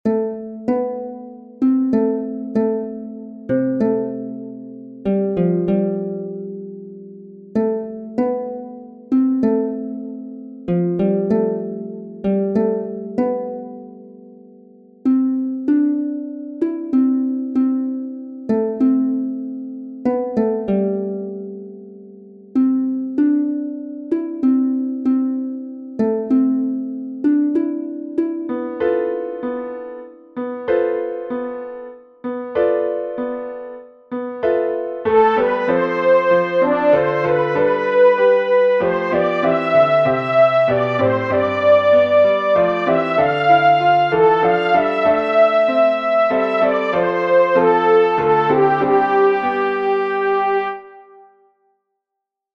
Voice part practice (top of page 4 to the top of page 7):
SOPRANO
dream_isaiah_saw-soprano.mp3